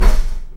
Index of /90_sSampleCDs/Roland - Rhythm Section/PRC_Trash+Kitch/PRC_Trash menu
PRC DOOR 01R.wav